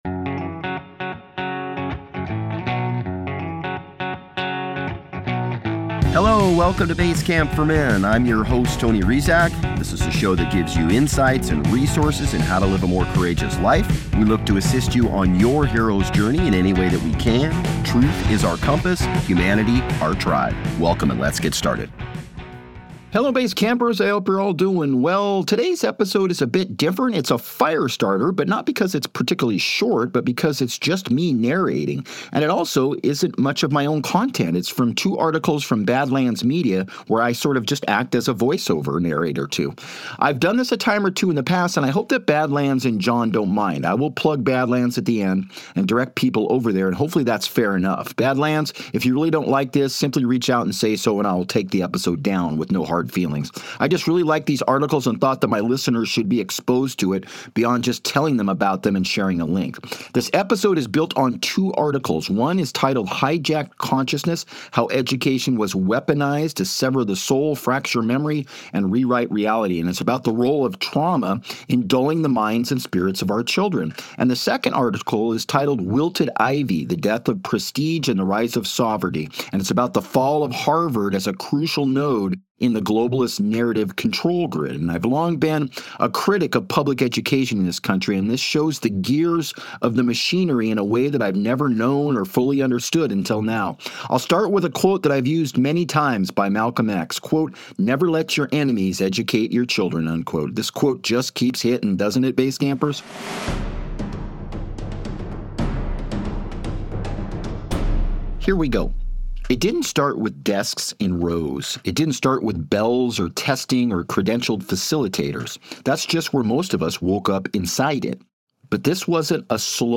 It is a Firestarter, not because it is short, but because it is just me narrating.
This is from two articles from Badlands Media where I simply act as a voiceover narrator.